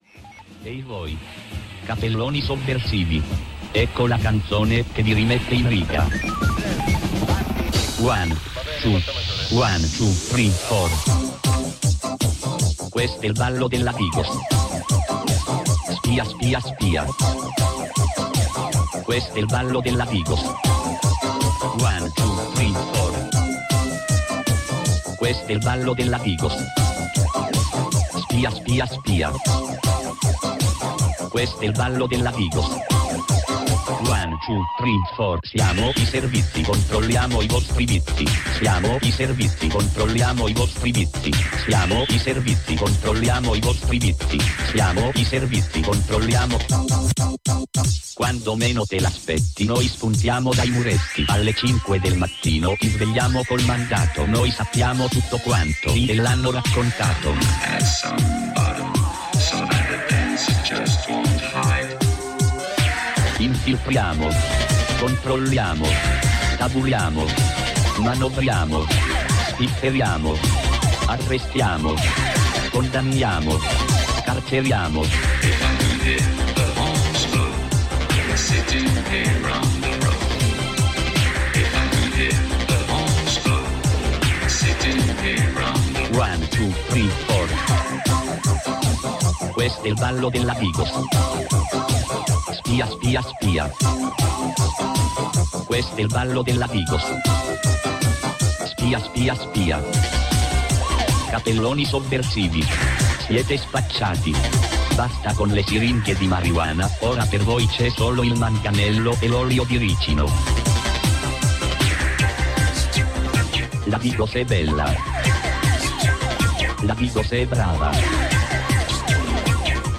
Dalla seconda ora di trasmissione, consueto aggiornamento da Barcellona in merito alle nuove leggi repressive in approvazione. Selezione musicale